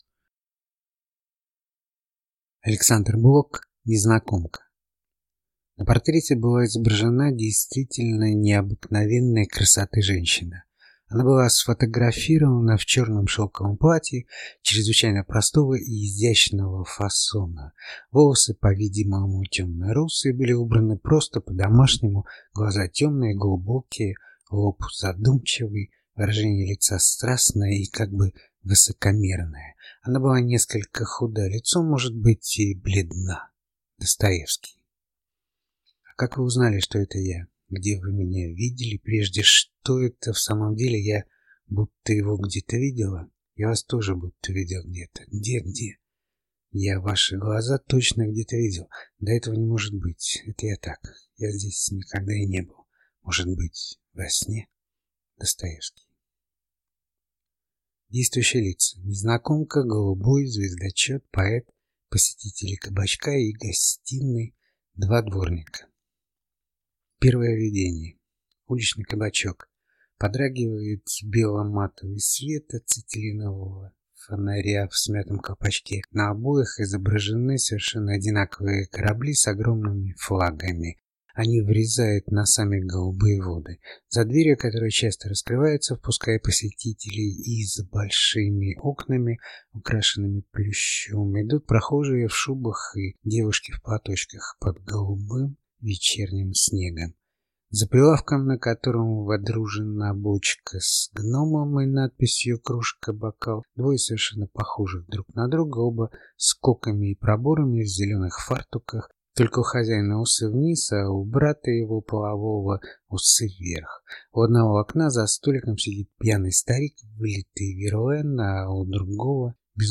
Аудиокнига Незнакомка | Библиотека аудиокниг